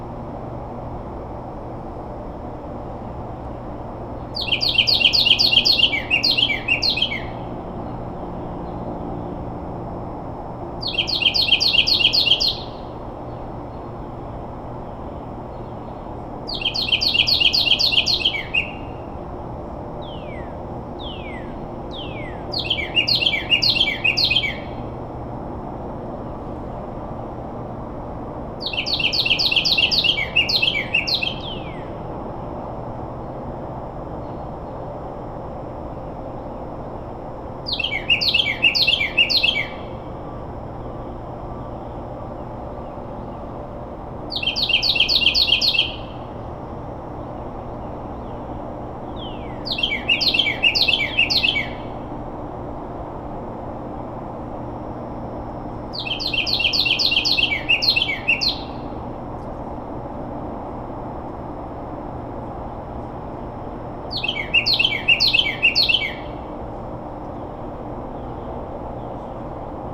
I decided to go out on 3/10 and record our little male friend again.
I ventured out a little later this time (5:45 instead of 5:15 a.m.). There wasn’t any wind or wind chime noise. A Northern Cardinal sang in the background, and the railyard was quiet. Here’s the song.
carolina-wren-31021.wav